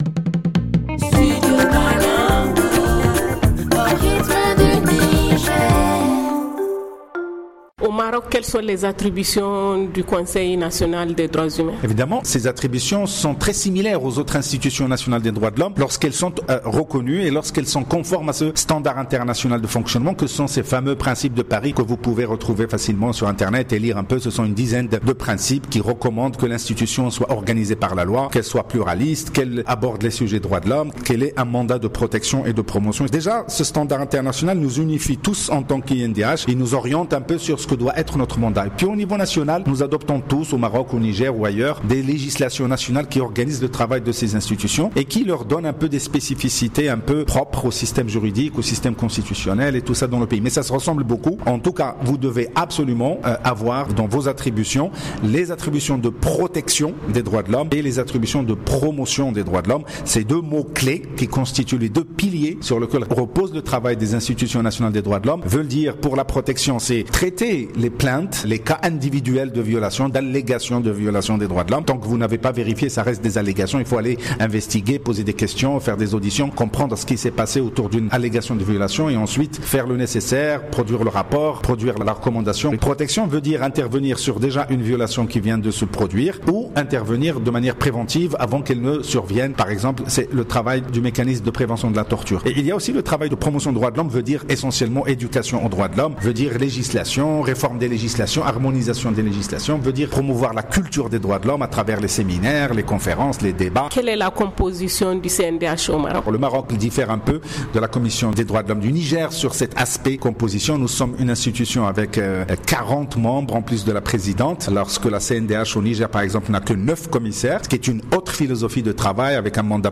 FR Le magazine en français https